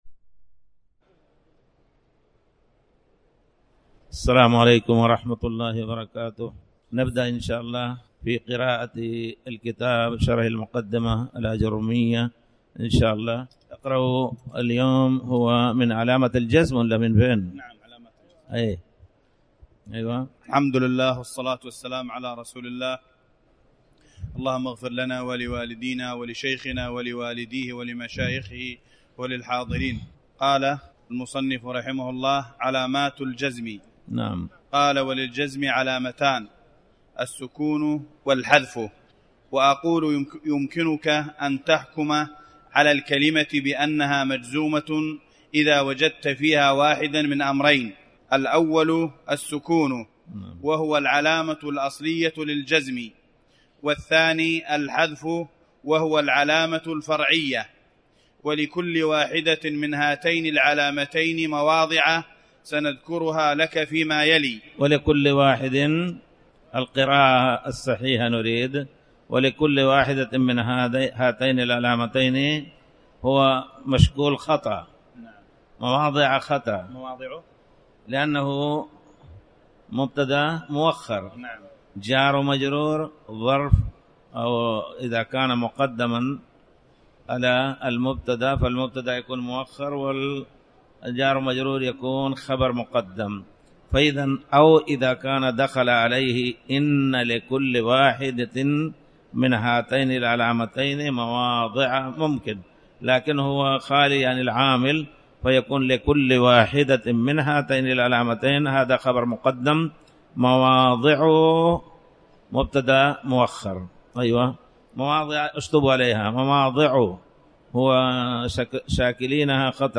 تاريخ النشر ٢٨ رجب ١٤٣٩ هـ المكان: المسجد الحرام الشيخ